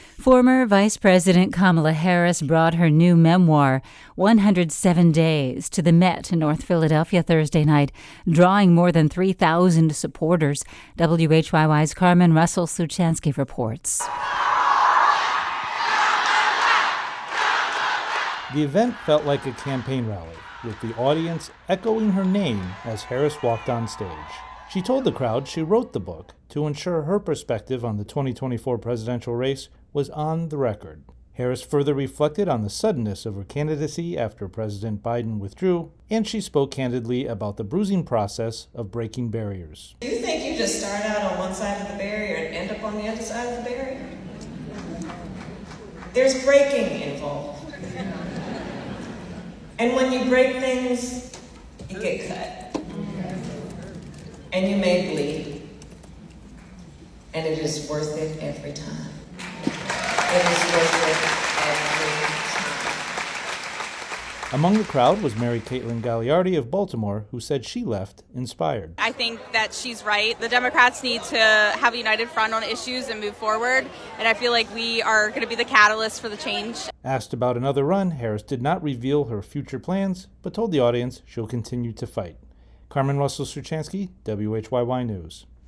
Last Thursday, a memorial service to honor the life of late Northwest Philadelphia activist and City Councilman Edward Schwartz was held at the National Consitution Center.